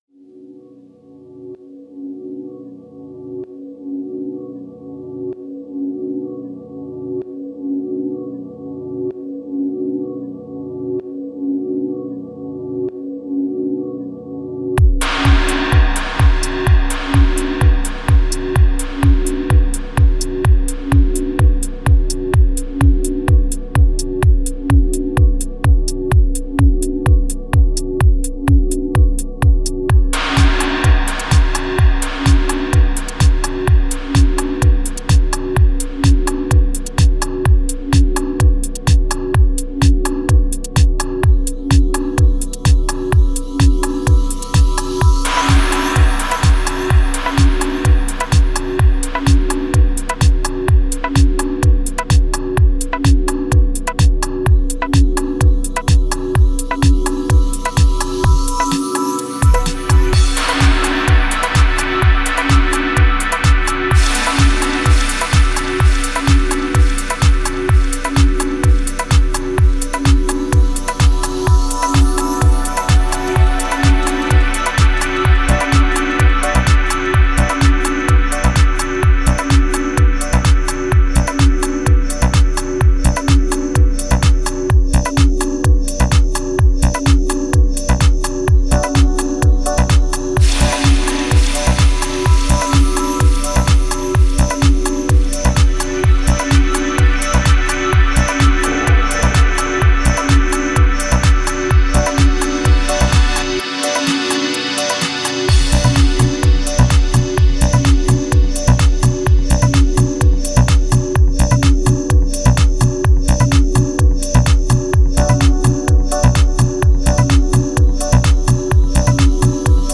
Жанр:Psychadelic